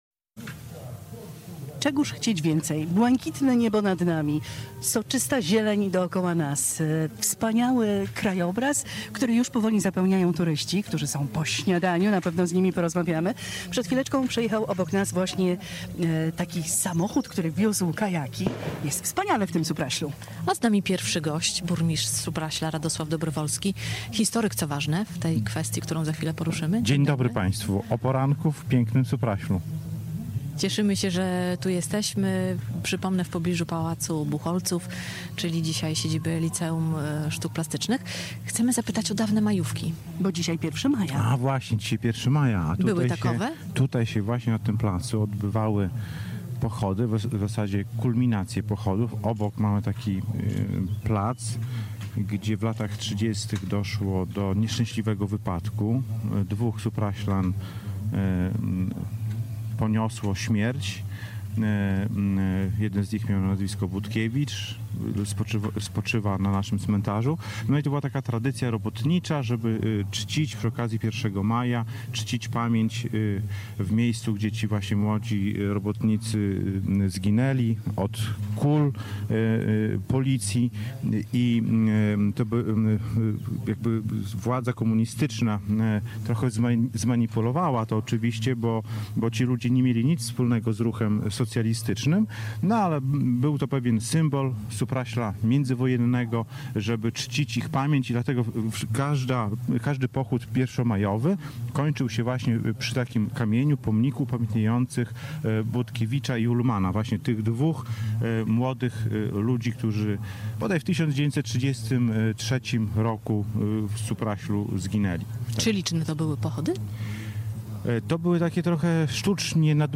Rozmowa z Radosławem Dobrowolskim, burmistrzem Supraśla o historii miasteczka | Pobierz plik.
Z naszego plenerowego studia ustawionego przed Pałacem Buchholtzów zachęcaliśmy do spacerowania po miasteczku i relaksu w okolicznych lasach.